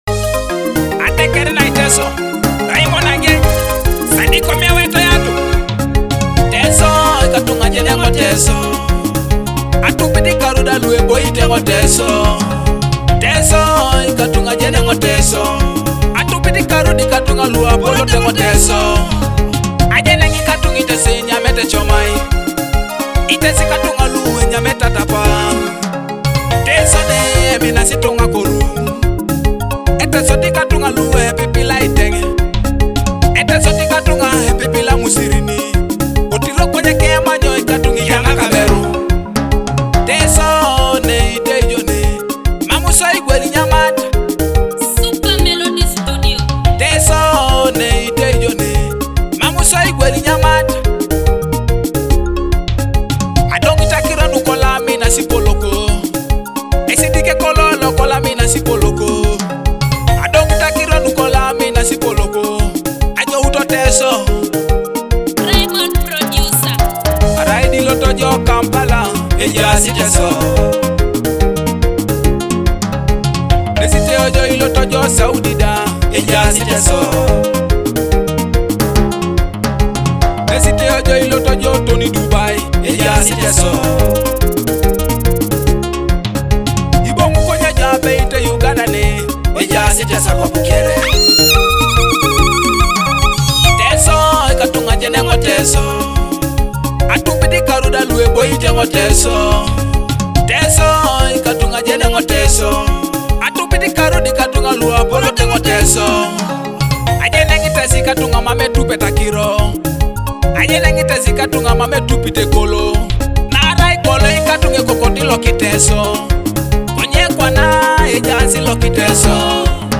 With heartfelt lyrics and a rich Afro-fusion sound